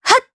Isaiah-Vox_Attack2_jp.wav